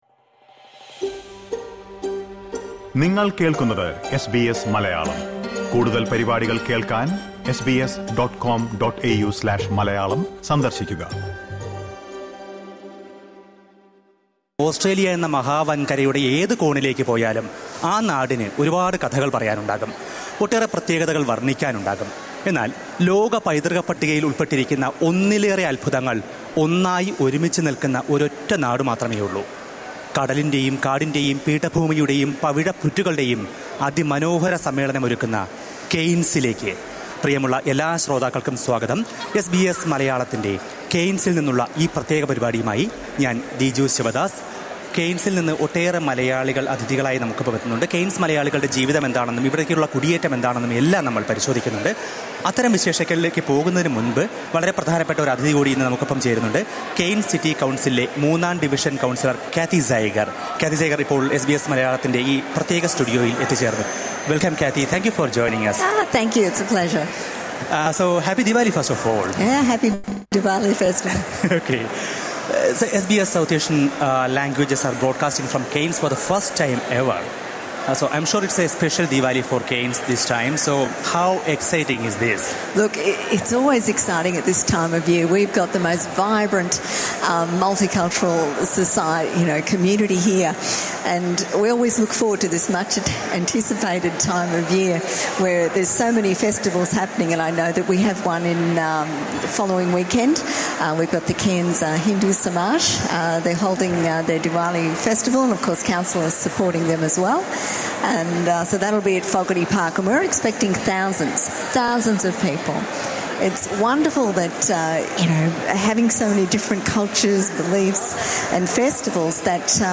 വടക്കൻ ക്വീൻസ്ലാൻറിലെ കെയിൻസ് നഗരത്തിന്റെ വിശേഷങ്ങളുമായി എസ് ബി എസ് മലയാളം അവിടെ നിന്ന് ഒരു പ്രത്യേക തത്സമയ പ്രക്ഷേപണം ഒരുക്കിയിരുന്നു. കെയിൻസ് നഗരത്തിന് ഇന്ത്യൻ വംശജർ നൽകുന്ന സംഭാവനകൾ അമൂല്യമാണെന്നും, കുടിയേറ്റക്കാർക്ക് ഈ മനോഹര നഗരം എന്നും സ്വാഗതമോതുമെന്നും പരിപാടിയിൽ പങ്കെടുത്ത കെയിൻസ് മൂന്നാം ഡിവിഷൻ കൌൺസിലർ കാത്തി സൈഗർ പറഞ്ഞു.